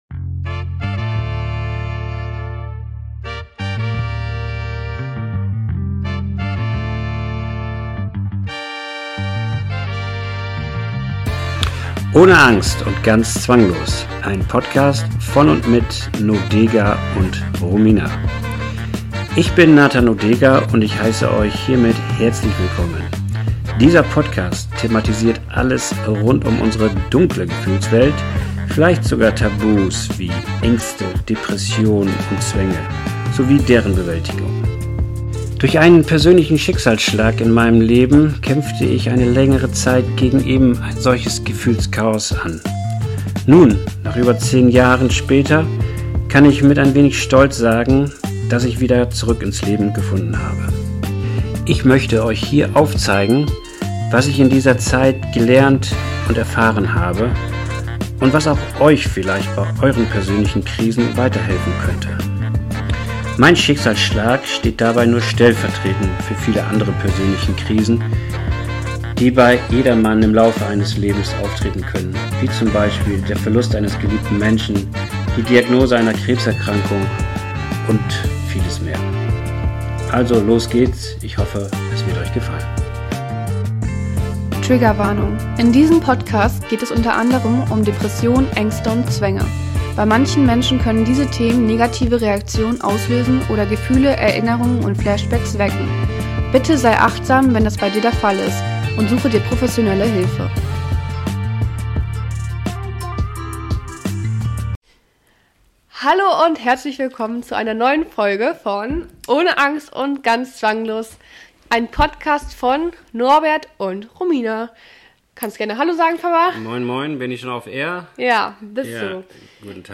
Zwischen Kontrolle, Angst und Akzeptanz – ein persönlicher Austausch zwischen Vater und Tochter über einen inneren Kampf, der viele betrifft, aber selten besprochen wird.